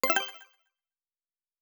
Special & Powerup (42).wav